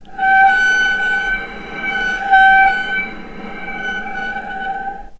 vc_sp-G5-mf.AIF